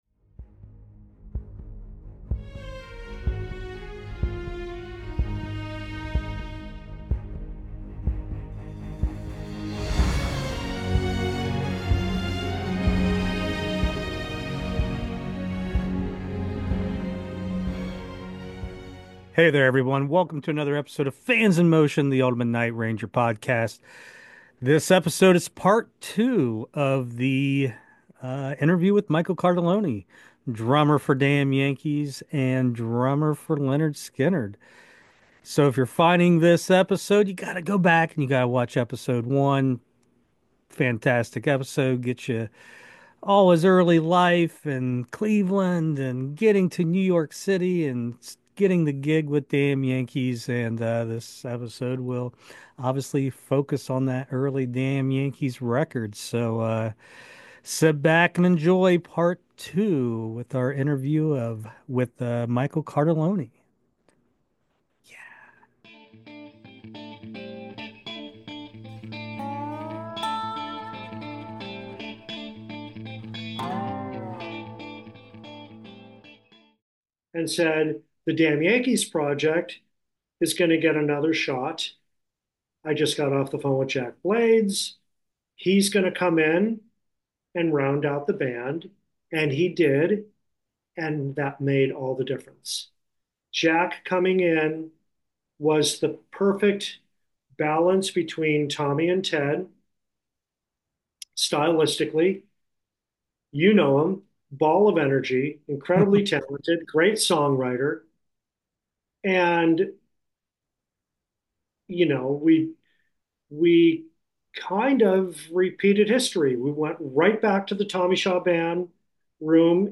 Here is Part 2 with of the interview with Michael Cartellone! In this episode we talk about the recording and the touring of the first Damn Yankees record!